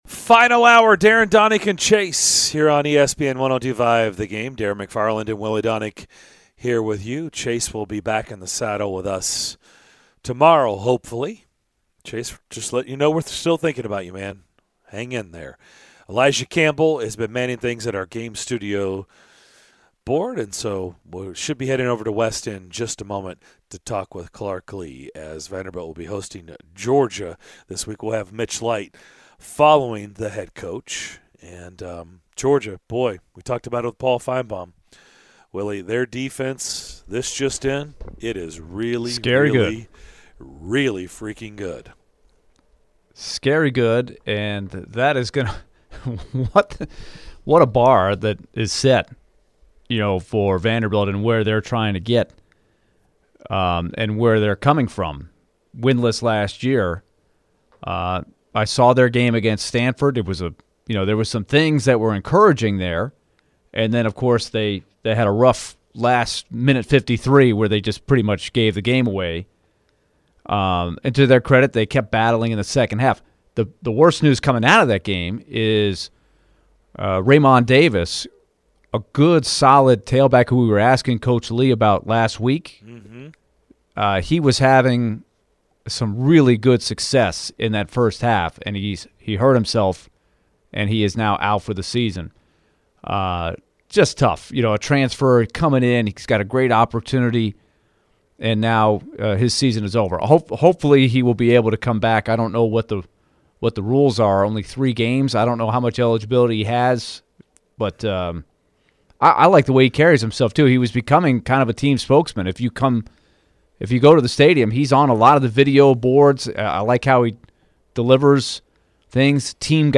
Vanderbilt Head Football Coach Clark Lea joined the DDC to discuss his team's growth over the course of three games, the loss to Stanford and more ahead of their big showdown with Georgia on Saturday!